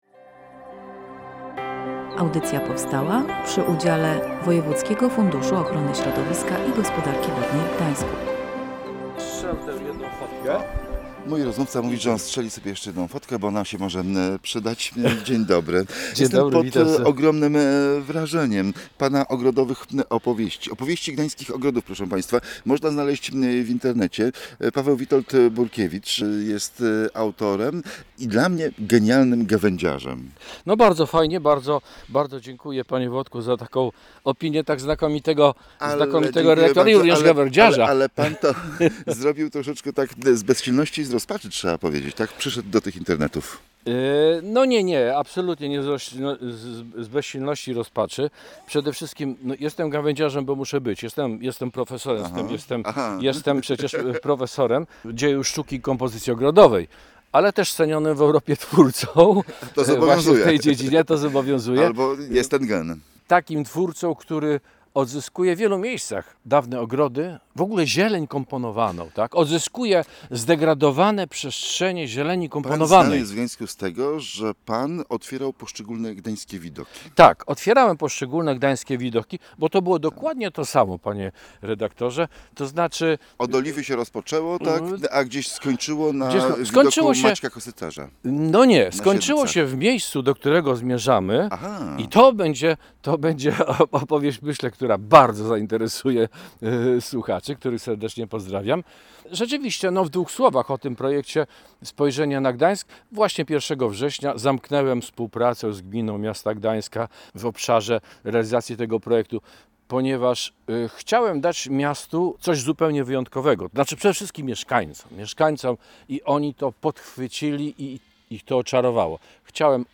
Magazyn Ekologiczny